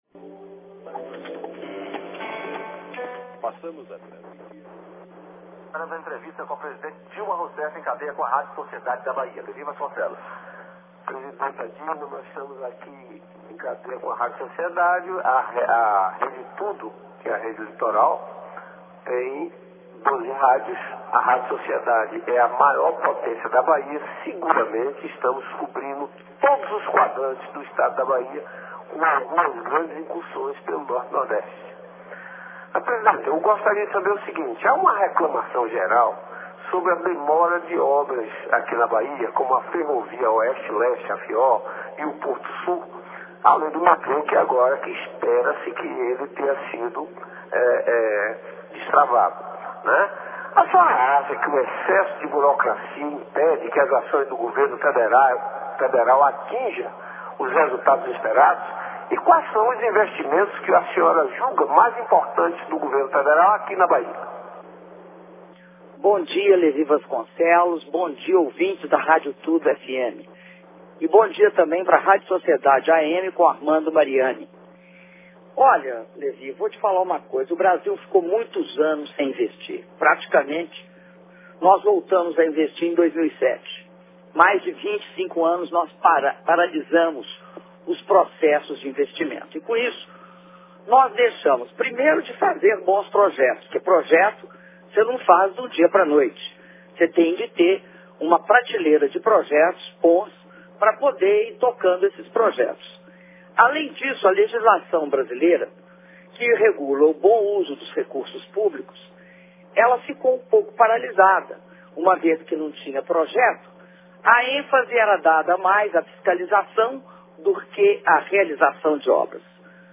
Entrevista concedida pela Presidenta da República, Dilma Rousseff, para as emissoras de rádio Tudo FM e Sociedade AM, da Bahia - Brasília/DF (29 min)